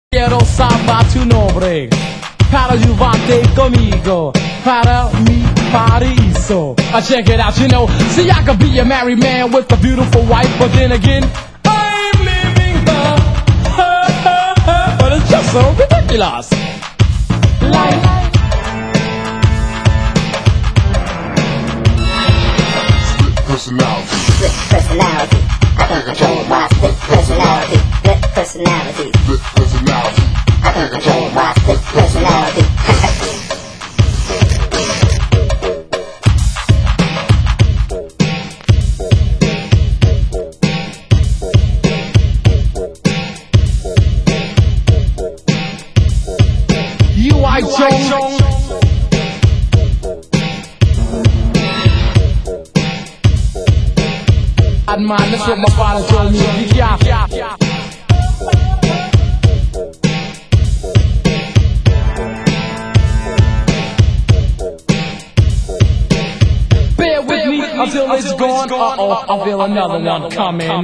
Genre: Hip Hop